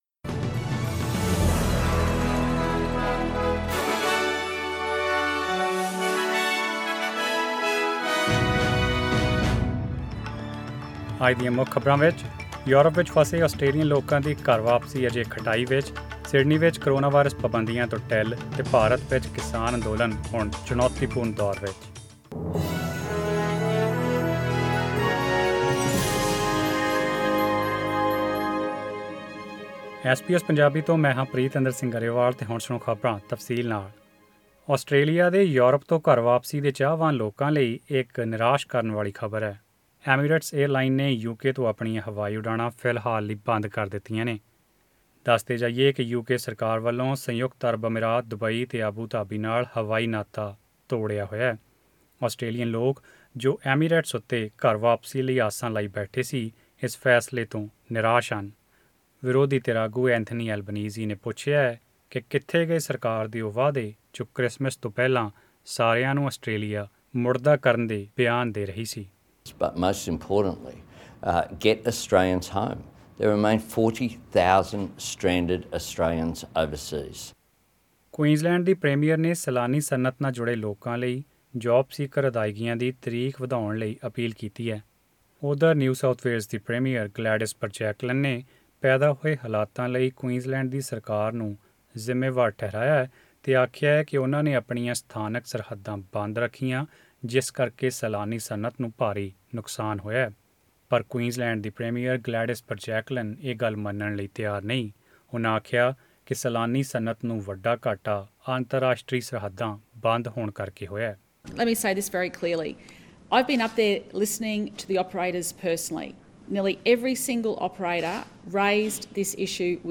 Australian News in Punjabi: 29 January 2021